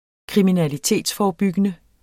Udtale [ kʁiminaliˈteˀdsˌfɒːɒˌbygənə ]